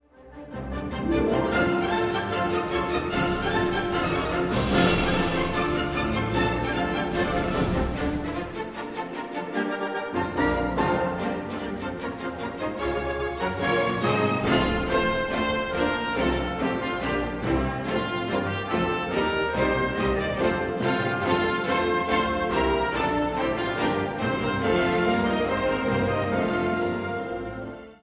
From both the complete work and the Aristophanic Suite Vaughan Williams later drew from it, only the Overture to The Wasps, with its signature opening insect buzzes, gets performed with any regularity today.